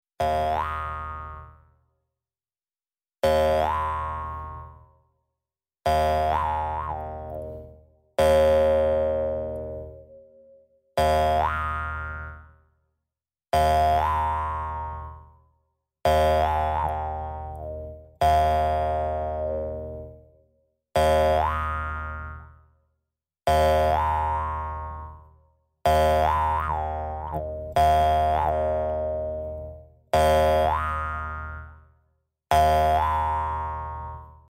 Его вибрирующие, гипнотические тона идеальны для медитации, релаксации и погружения в этническую атмосферу.
Звуки для глубокой медитации